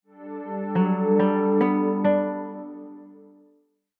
Звуки уведомлений Samsung